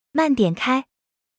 audio_speed_down.wav